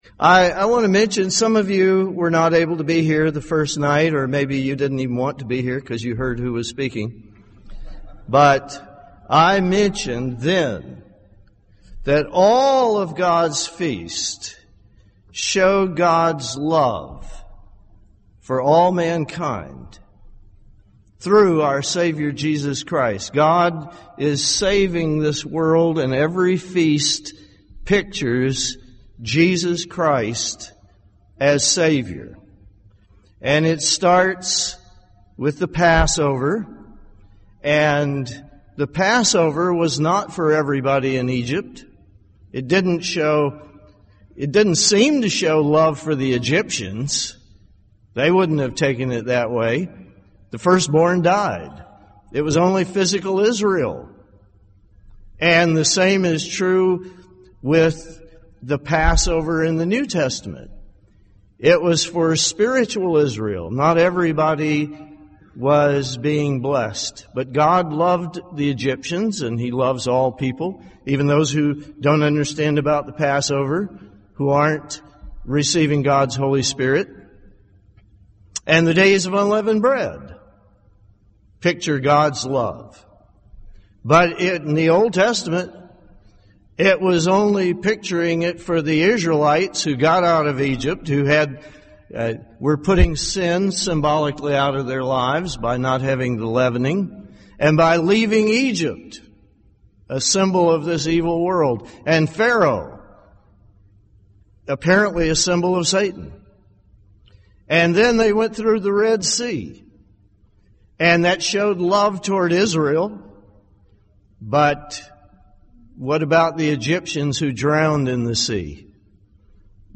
This sermon was given at the Jekyll Island, Georgia 2014 Feast site.